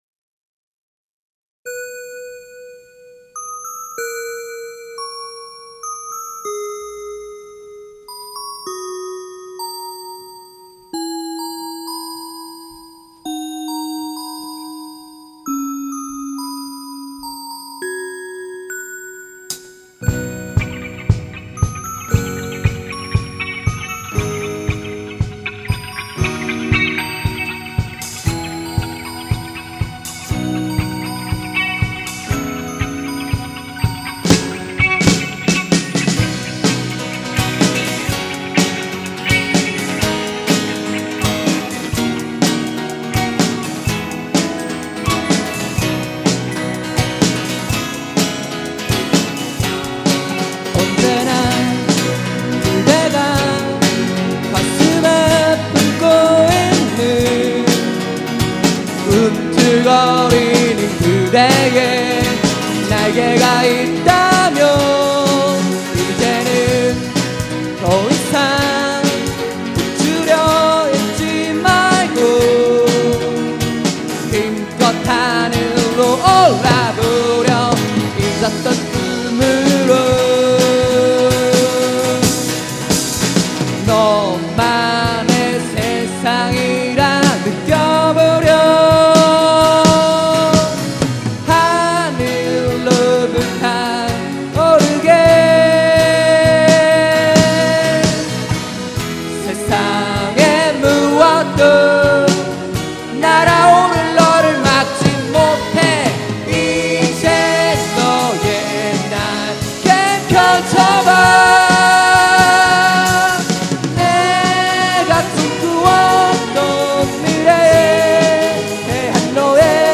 2004년 제 24회 정기 대공연
홍익대학교 신축강당
노래
어쿠스틱기타
일렉트릭기타
드럼
신디사이저